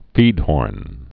(fēdhôrn)